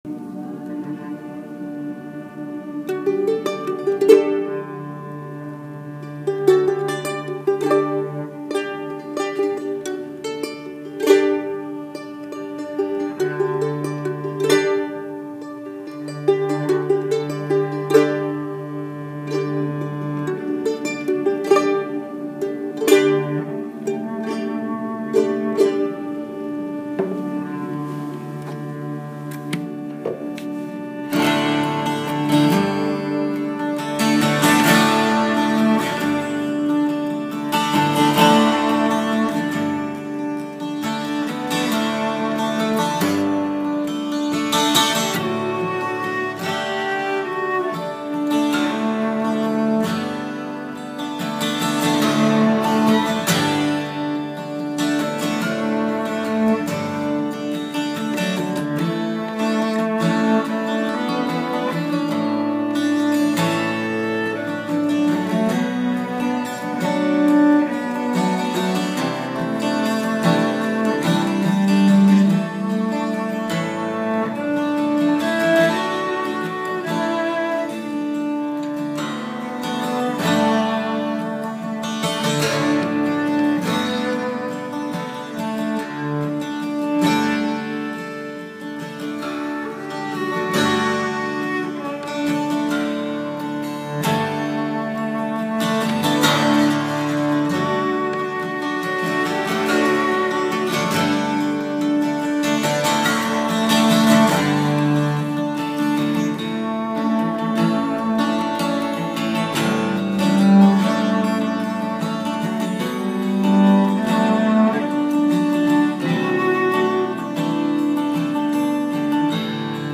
vocals, cello, keyboards, acoustic bass, guitar
guitars, cuatro/charango, Irish bouzouki, vihuela, saxophone